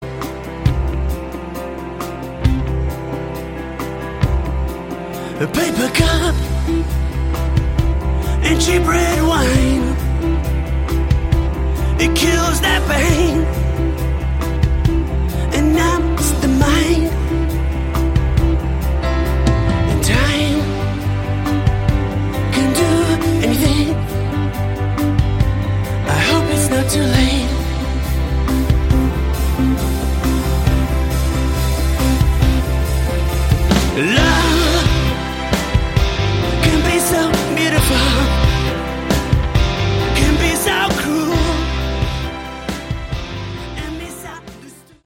Category: Hard Rock
lead and backing vocals, guitar, piano
guitars, backing vocals
drums, percussion
bass